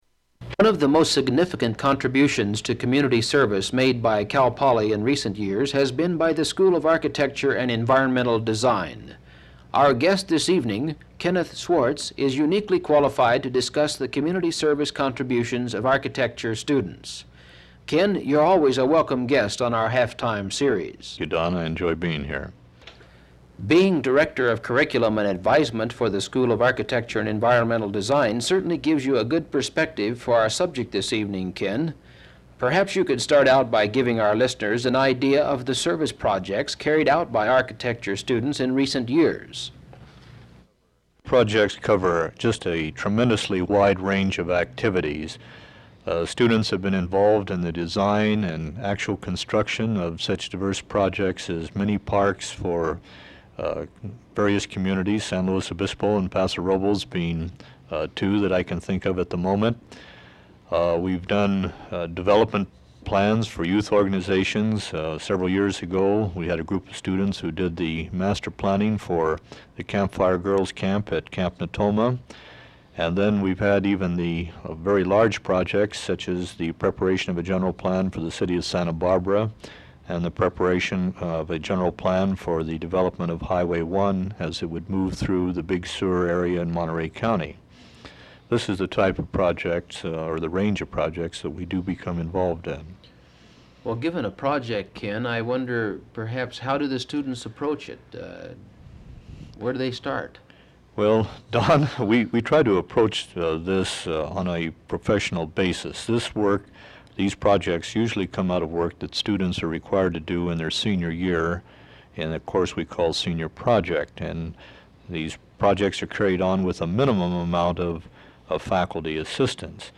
• Open reel audiotape